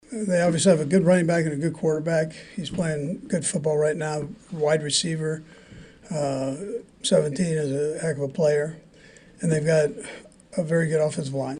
KC Chiefs coach Andy Reid says it isn’t a surprise the Panthers are moving the football on offense